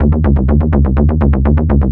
Index of /90_sSampleCDs/Club_Techno/Bass Loops
BASS_124_D.wav